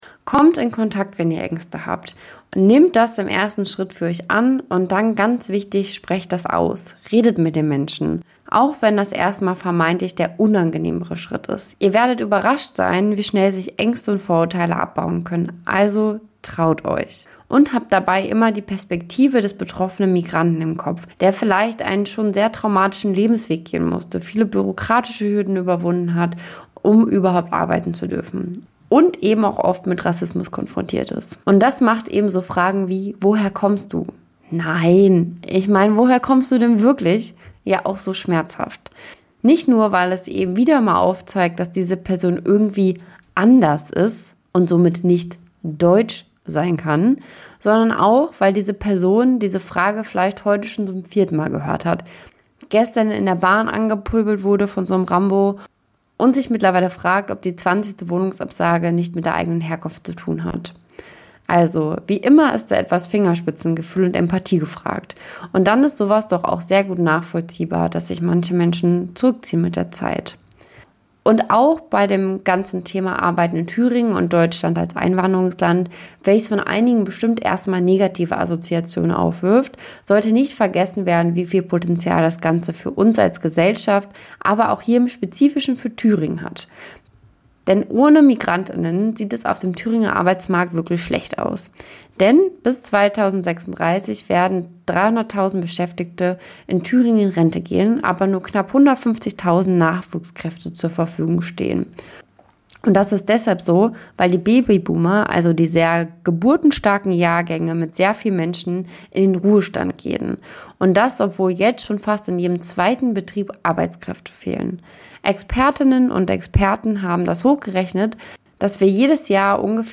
Boogie-Woogie - am Klavier 1 circa 1940